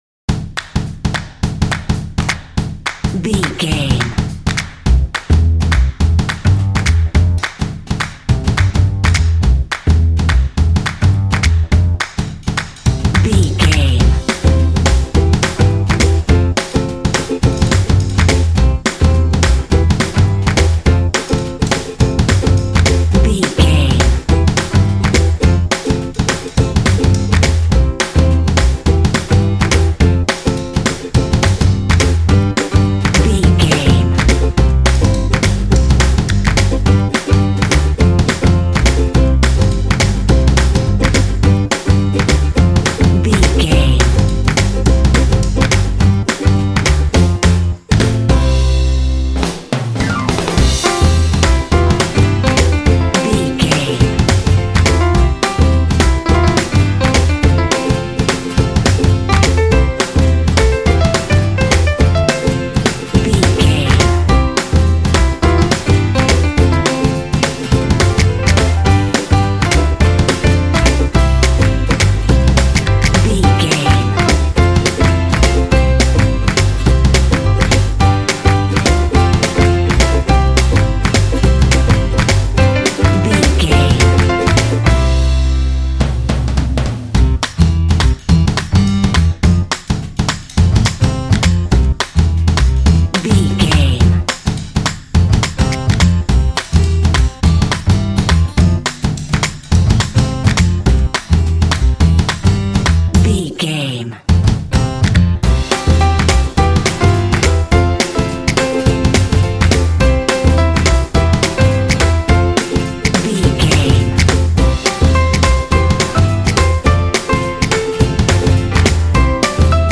Uplifting
Ionian/Major
bouncy
energetic
groovy
acoustic guitar
drums
piano
percussion
double bass
pop
contemporary underscore